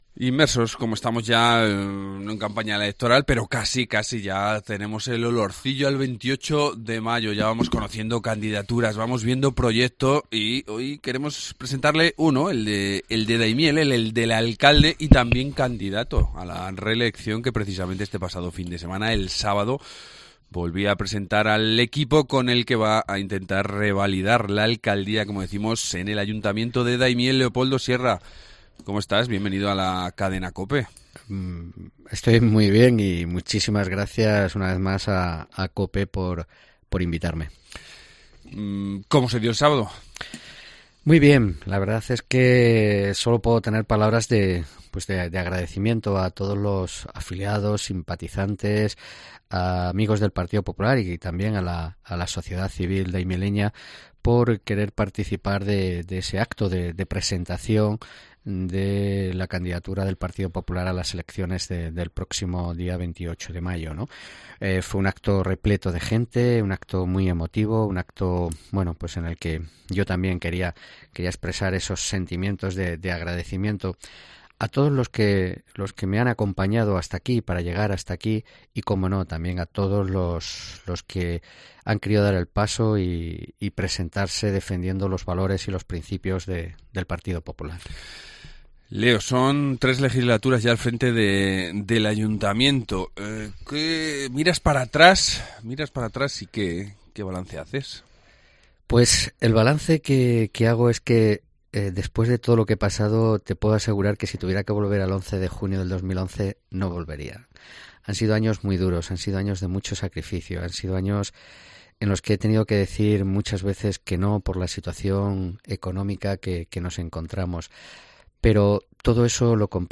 Leopoldo Sierra, alcalde de Daimiel
Entrevista